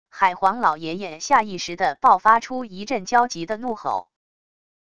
海皇老爷爷下意识的爆发出一阵焦急的怒吼wav音频生成系统WAV Audio Player